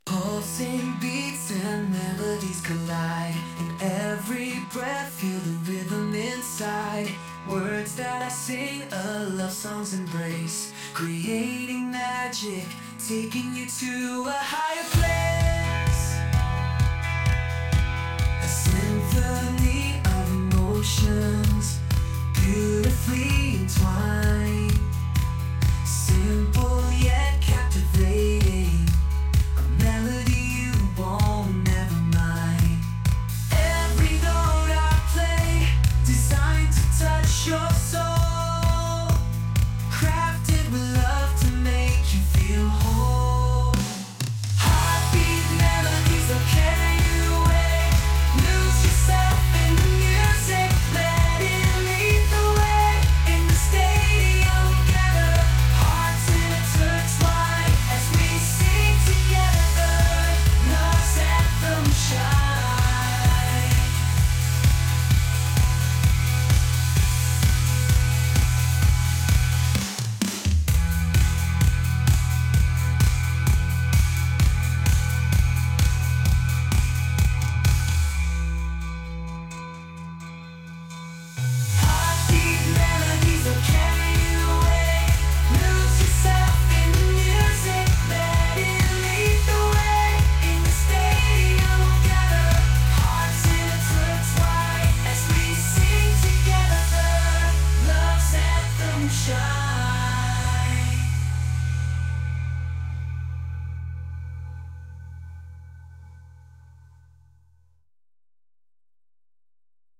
Electric Bass, Drums, Electric Guitars, Chorus
Genre: Blues and Rock